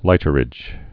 (lītər-ĭj)